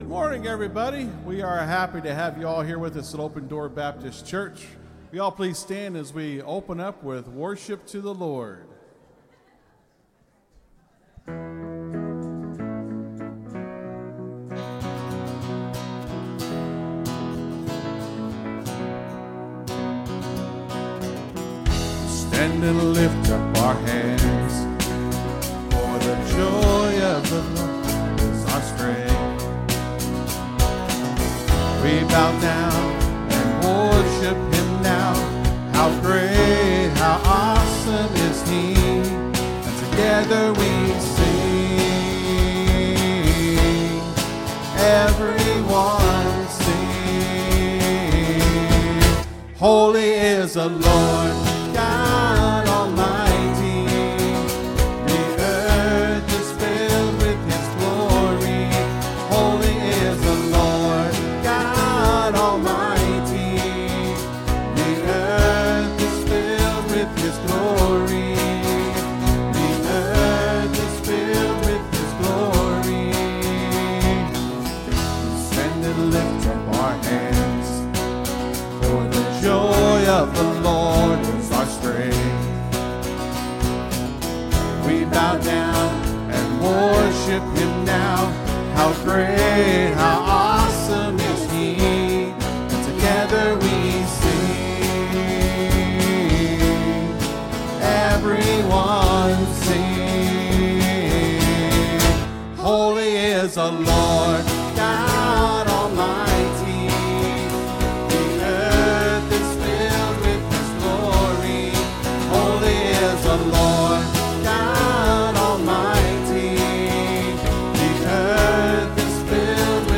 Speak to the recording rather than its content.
(Sermon starts at 24:15 in the recording).